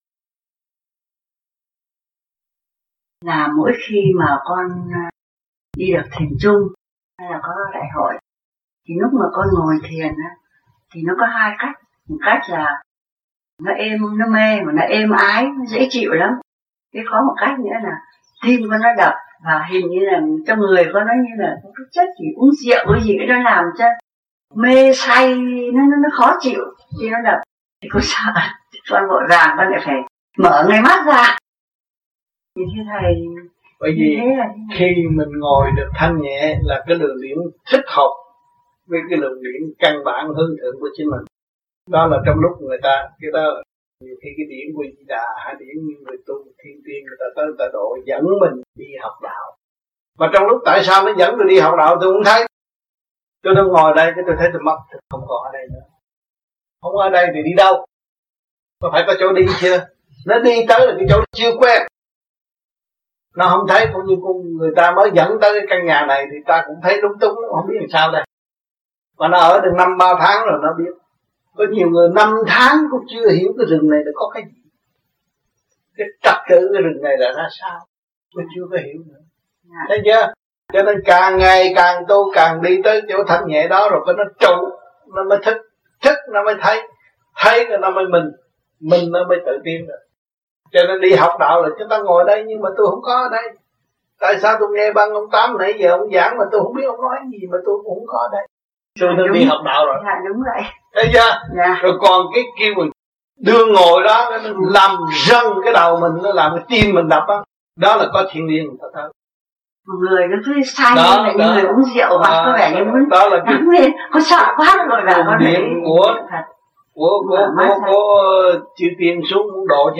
Lời giảng